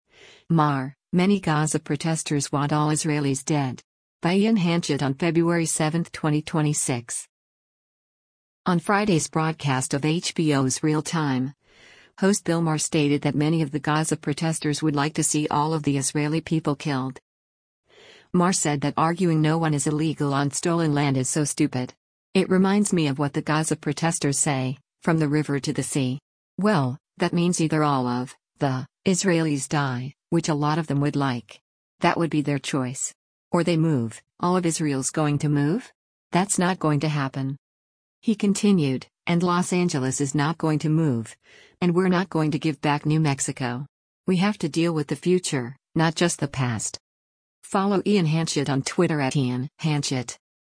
On Friday’s broadcast of HBO’s “Real Time,” host Bill Maher stated that many of the “Gaza protesters” would “like” to see all of the Israeli people killed.